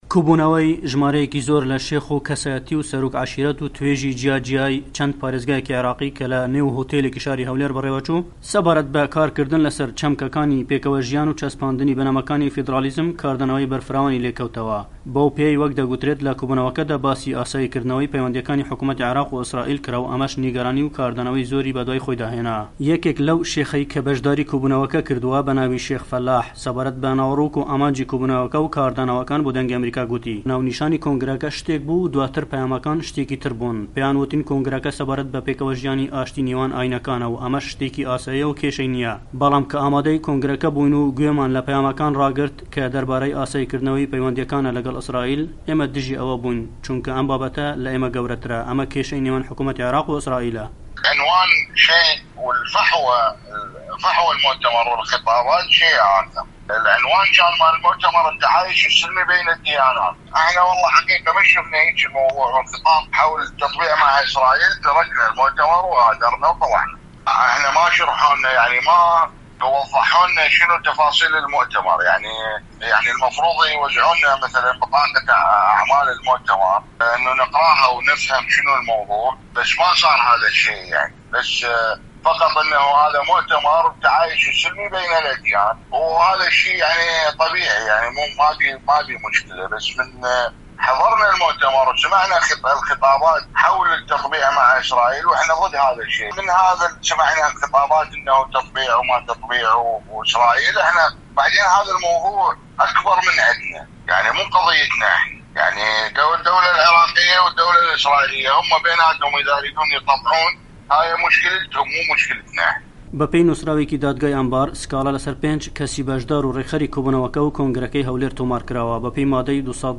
راپۆرت سەبارەت بە کۆبوونەوەکەی هەولێر و پەیوەندی لە گەڵ ئیسڕائیل